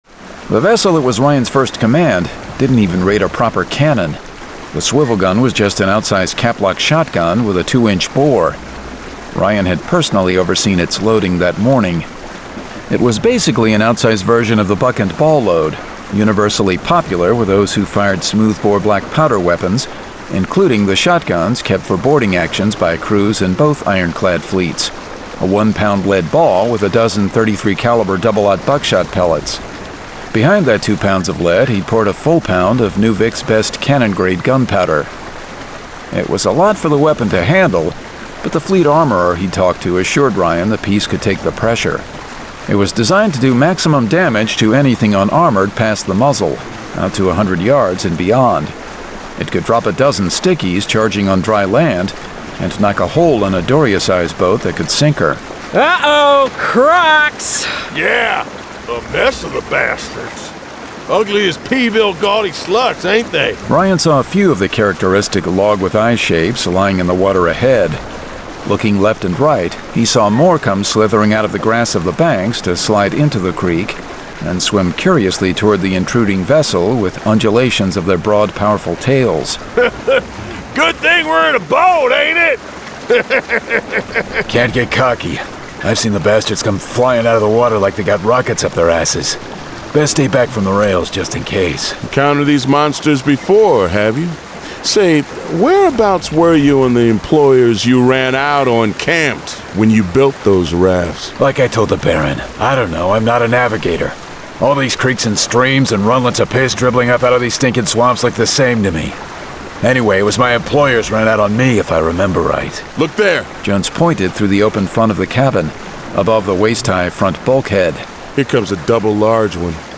Deathlands 123: Iron Rage [Dramatized Adaptation]